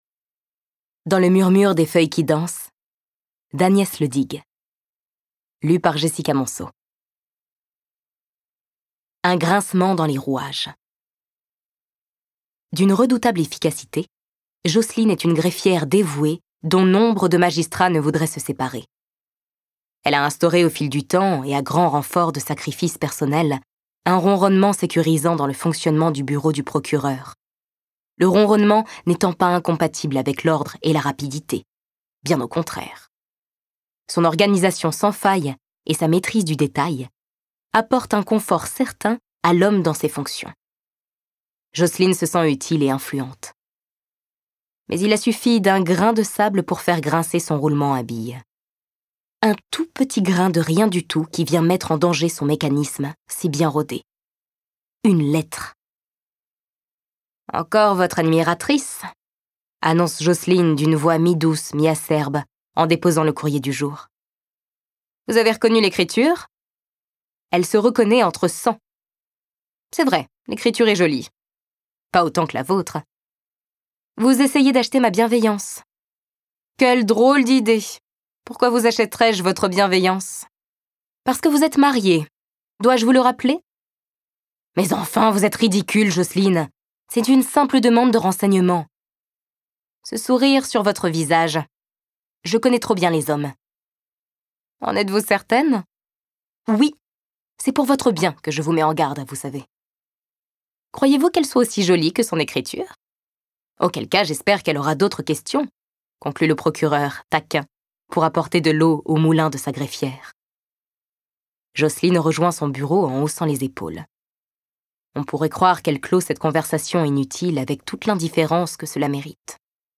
Extrait lu par